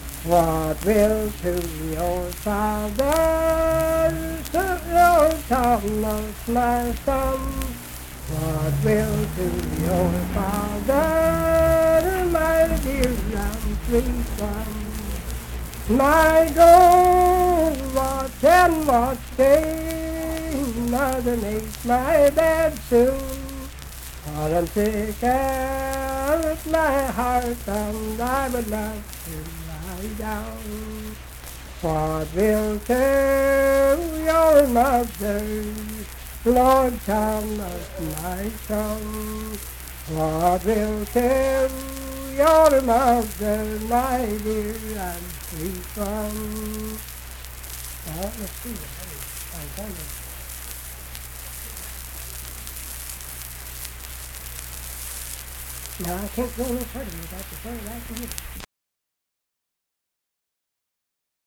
Unaccompanied vocal music
Verse-refrain 2(4).
Performed in Ivydale, Clay County, WV.
Voice (sung)